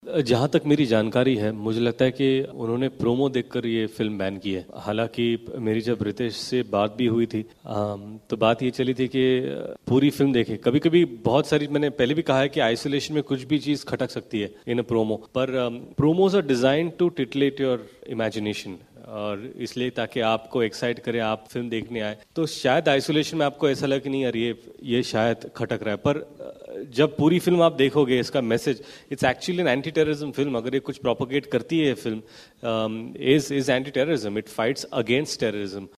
सुनिए क्या कहते हैं फ़िल्म 'बैंगिस्तान' के अभिनेता रितेश देशमुख फ़िल्म पर लगे बैन के बारे में.